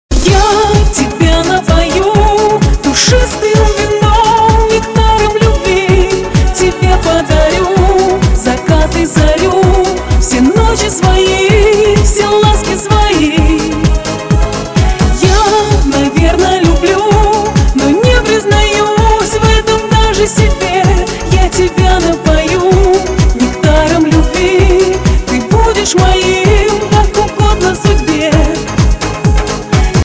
• Качество: 320, Stereo
поп
женский вокал
шансон